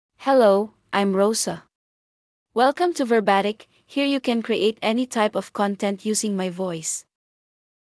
Rosa — Female English AI voice
Rosa is a female AI voice for English (Philippines).
Voice sample
Female
Rosa delivers clear pronunciation with authentic Philippines English intonation, making your content sound professionally produced.